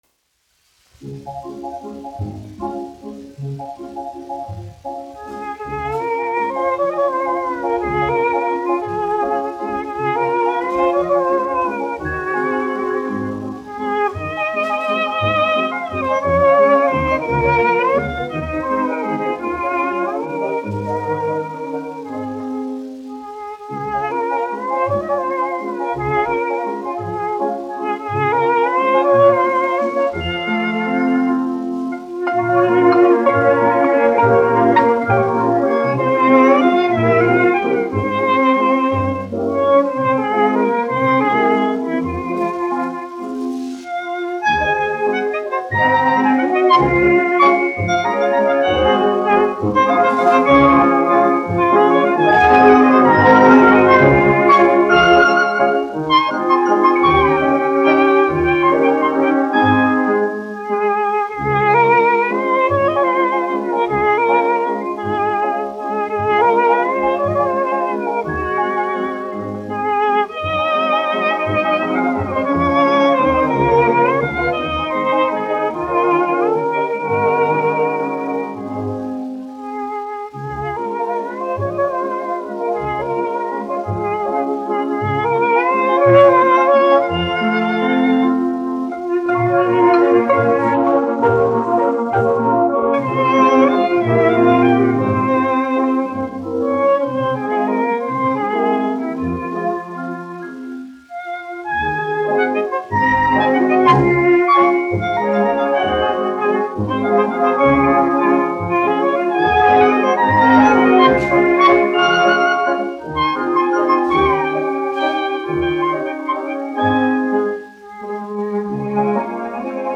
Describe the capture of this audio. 1 skpl. : analogs, 78 apgr/min, mono ; 25 cm Skaņuplate